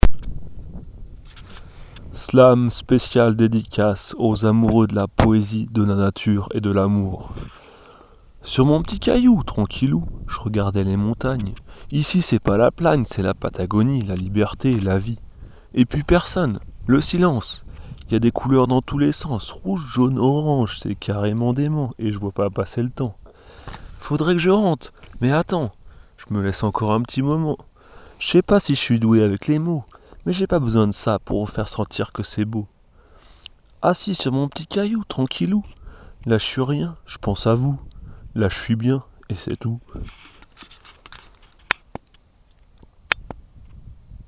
Slam a la montagne.MP3